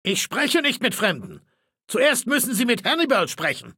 Datei:Maleold01 ms06 greeting 0005d5a0.ogg